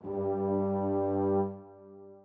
strings2_4.ogg